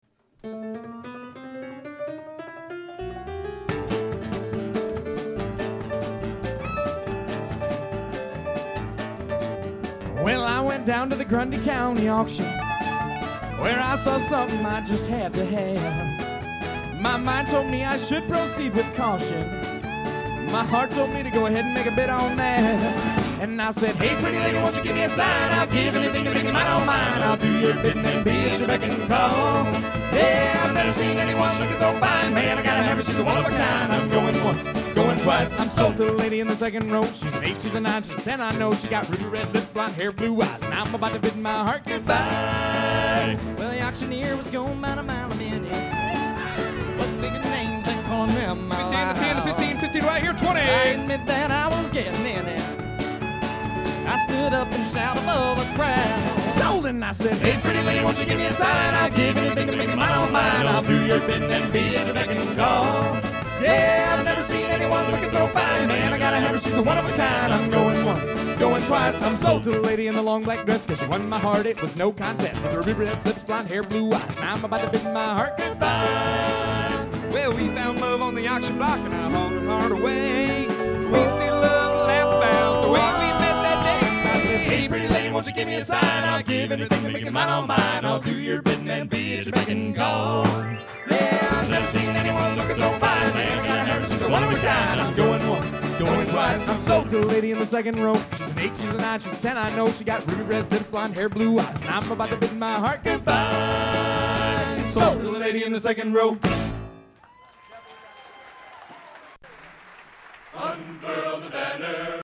Genre: Country/Western | Type: Featuring Hall of Famer